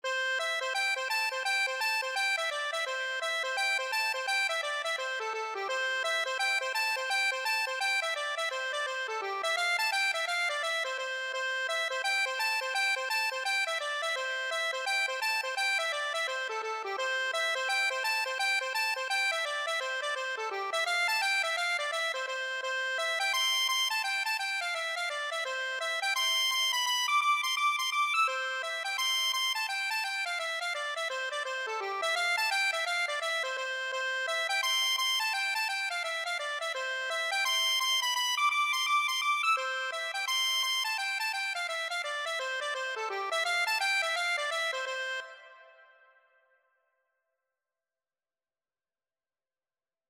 Traditional Trad. Humphrey's Hornpipe (Irish Folk Song) Accordion version
4/4 (View more 4/4 Music)
C major (Sounding Pitch) (View more C major Music for Accordion )
Accordion  (View more Easy Accordion Music)
Traditional (View more Traditional Accordion Music)